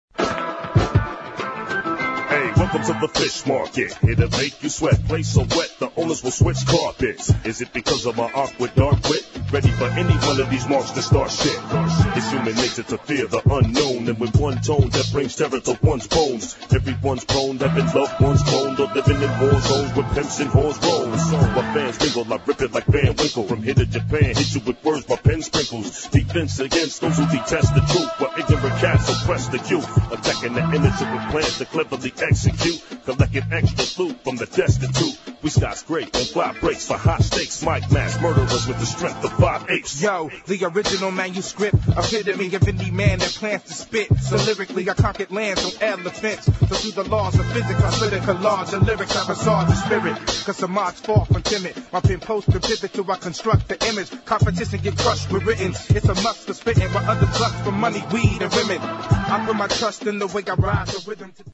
[ HIP HOP ]